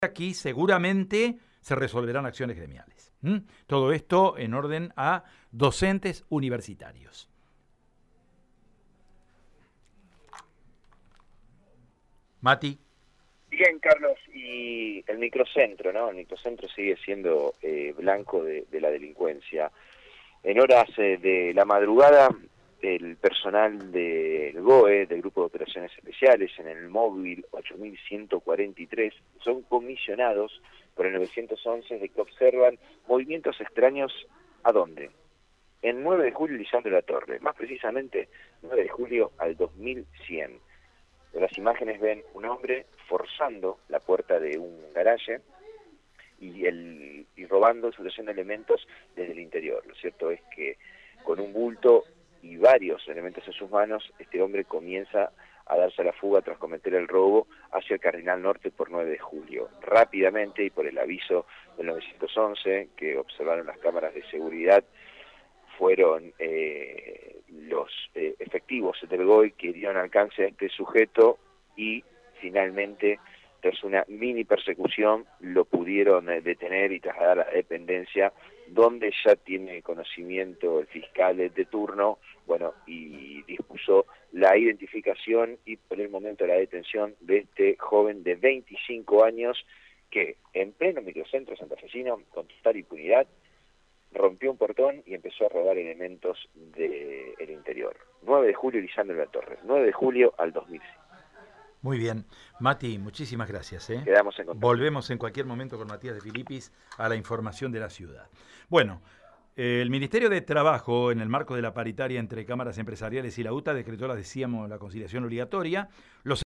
AUDIO DESTACADOPolicialesSanta Fe